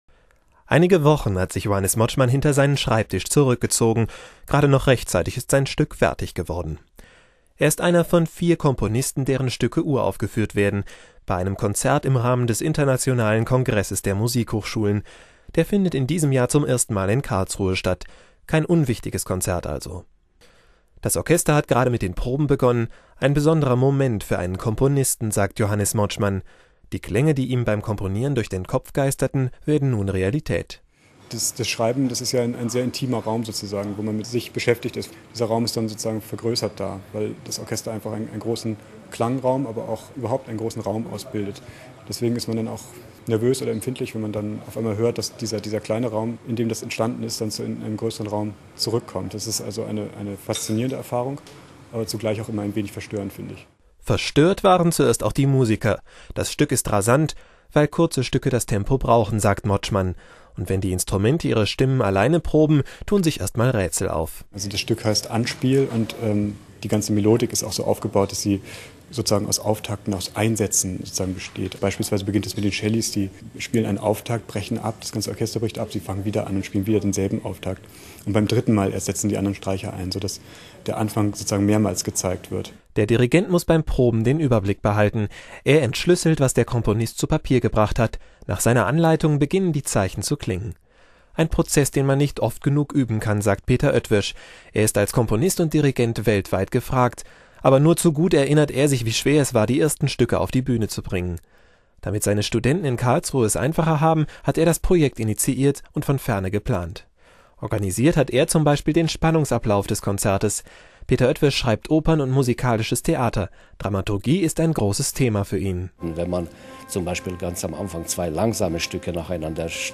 Orchester der Musikhochschule
Das Orchester der Musikhochschule spielt Musik von Jungen Karlsruhe Kompositionsstudenten.